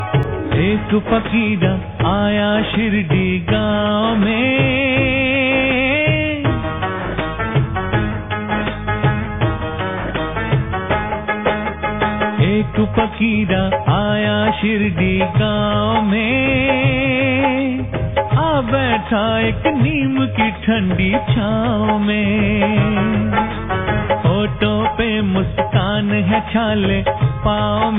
In Bhakti